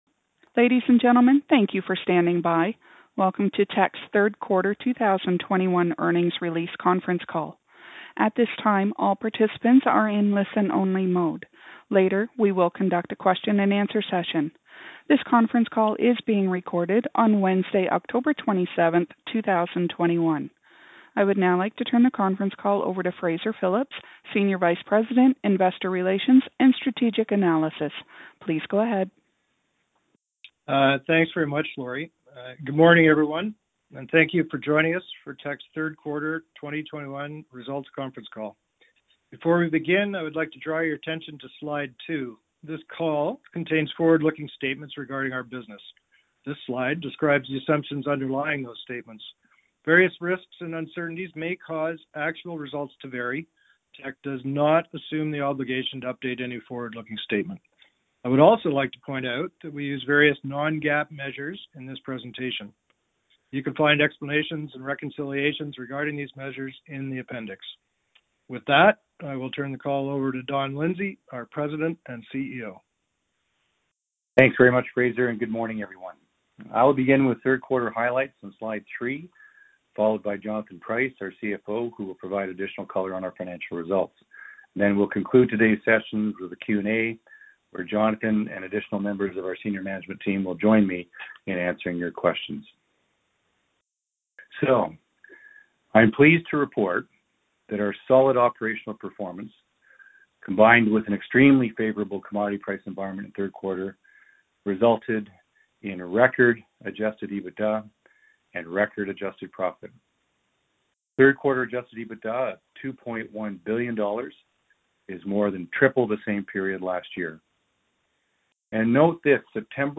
Q3-2021-Financial-Report-Conference-Call-Audio.mp3